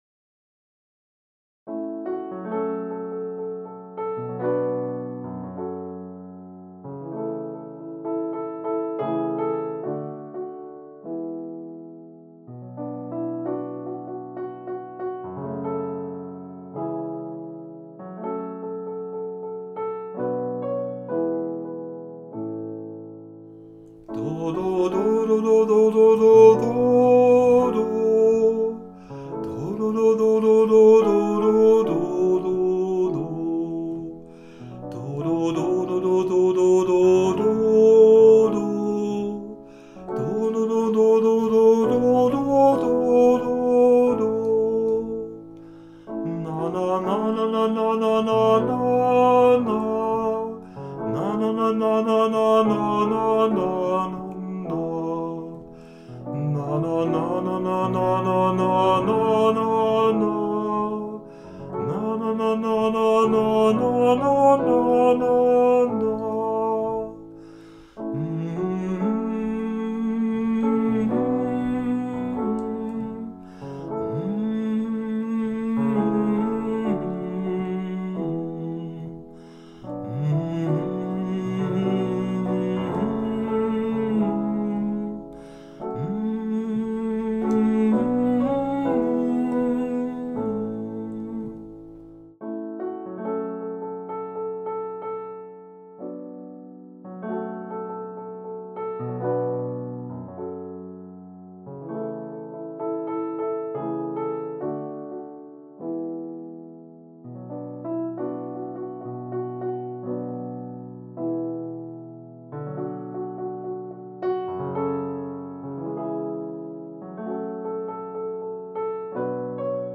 ohne Text, sondern nur mit Silben oder gesummt zu hören
Abschnitt 1: vierteilige Psalmodie, viermal gesungen bzw. gespielt
* E-Piano
* Summen mit mmmmh
psalmton-viii-vierteilig-uebung.mp3